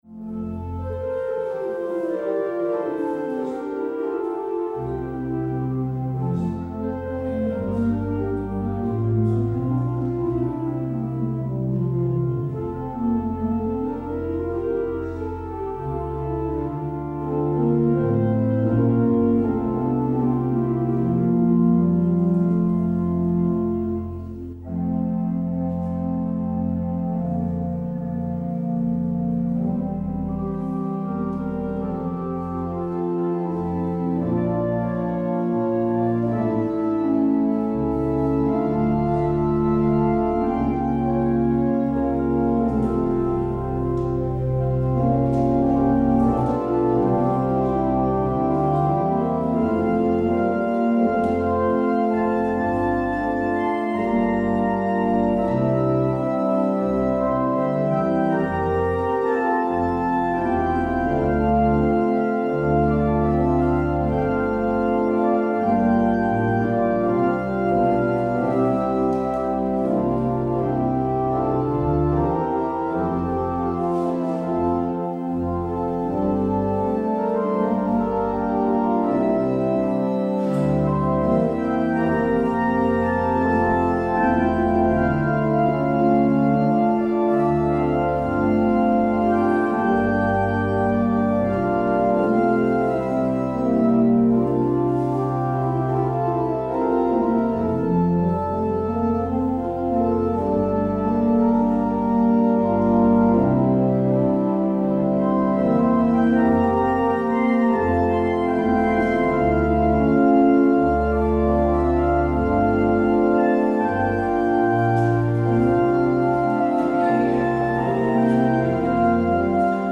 Luister deze kerkdienst hier terug:
Want dit is de wil van God in Christus Jezus voor u. Het openingslied is: LvdK 304: 1, 2 en 3.